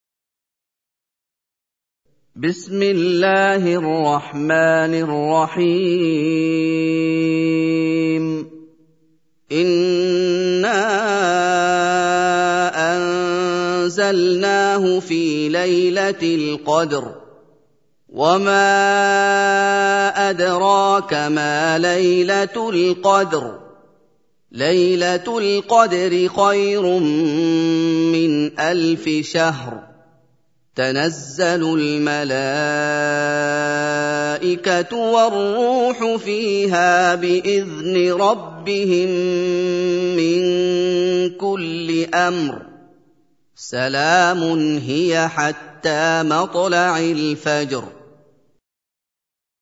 97. Surah Al-Qadr سورة القدر Audio Quran Tarteel Recitation
Surah Sequence تتابع السورة Download Surah حمّل السورة Reciting Murattalah Audio for 97. Surah Al-Qadr سورة القدر N.B *Surah Includes Al-Basmalah Reciters Sequents تتابع التلاوات Reciters Repeats تكرار التلاوات